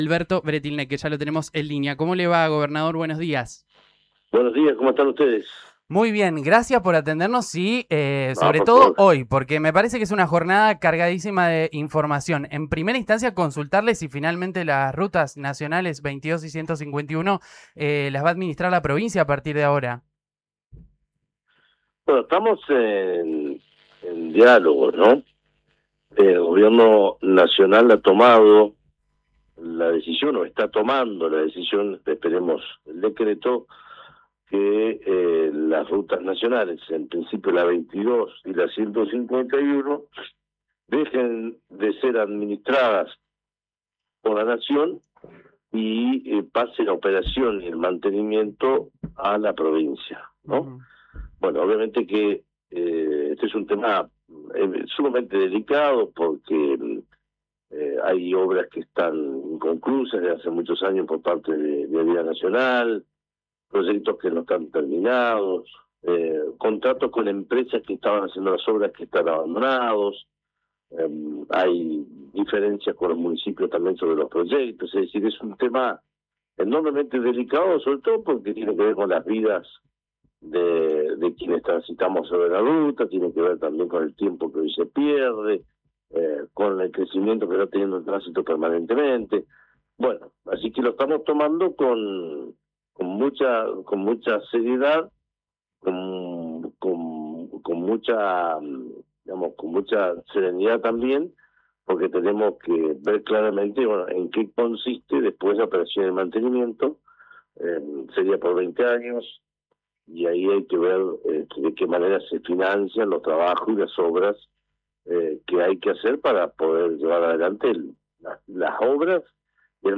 Según afirmó el gobernador Alberto Weretilneck en diálogo con Río Negro Radio, el decreto que confirma la transferencia «tendría que salir entre hoy y mañana” y aclaró que ese paso será solo el inicio de una etapa más compleja.